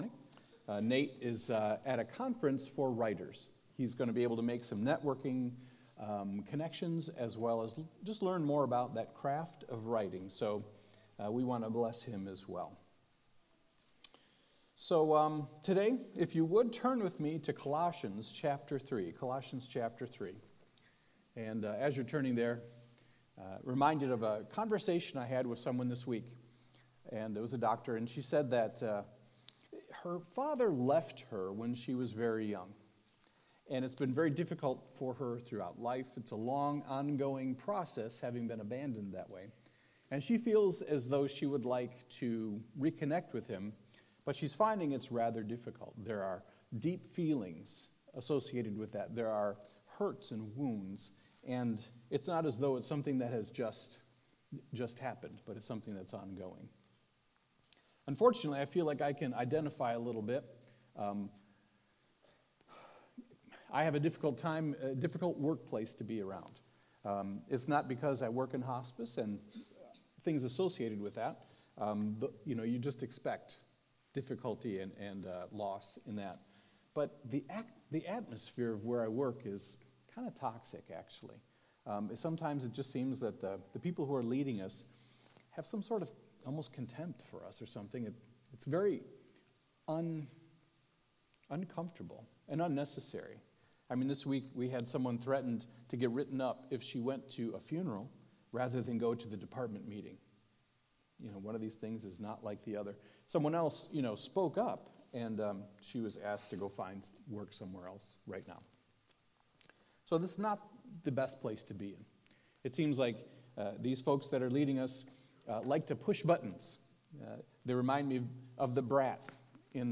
A message from the series "Special Guest Speakers."